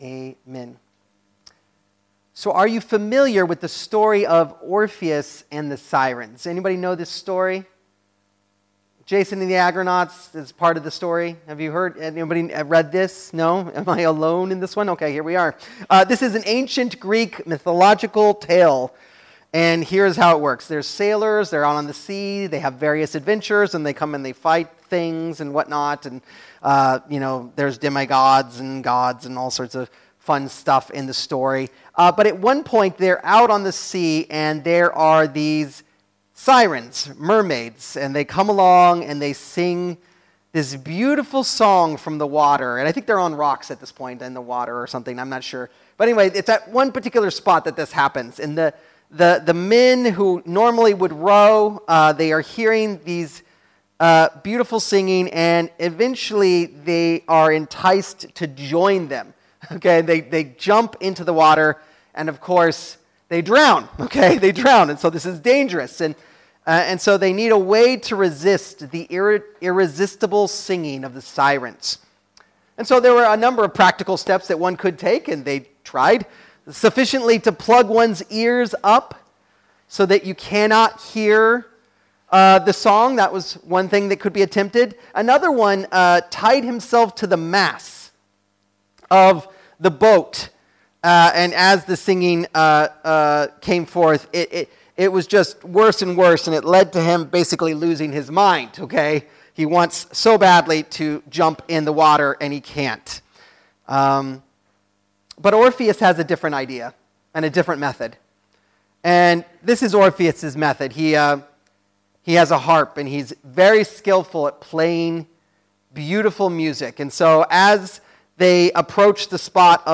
Sermons
Sermons Archive